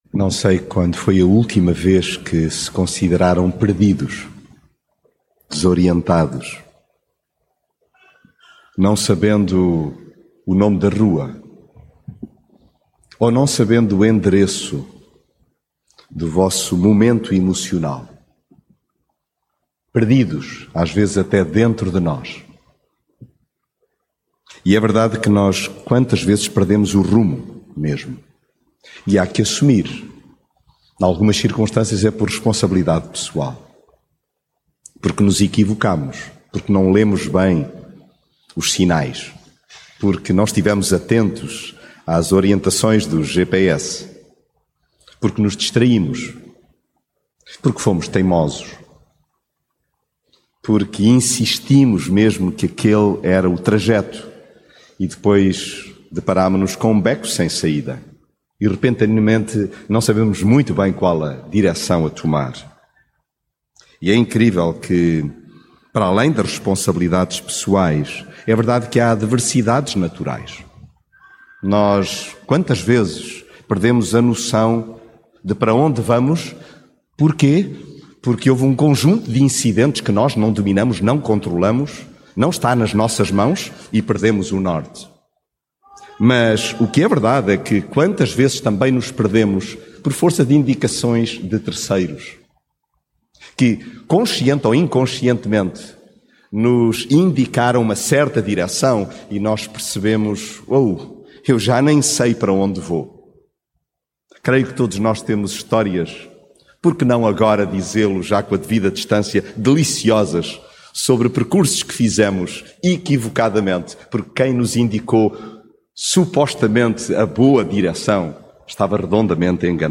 perguntas (in)convenientes mensagem bíblica Alturas há em que perdemos o rumo…